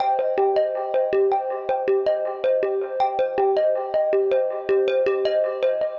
Ambient / Keys / SYNTH010_AMBNT_160_C_SC3(L).wav